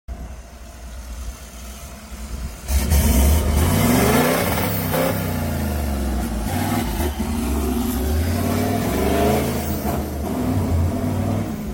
We love these 70 Series V8 Cruisers 😍🚀 This beast with a short exhaust straight pipe sounds insane!!